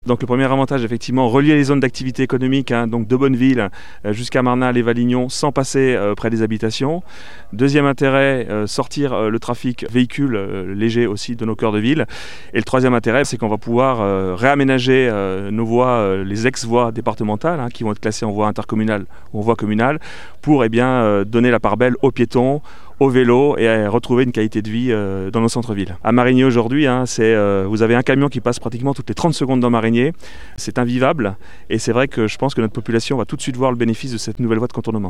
Le maire de Marignier, Christophe Pery, revient sur les avantages de cette ouverture pour sa commune.